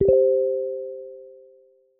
Звук оповещения в Телеграм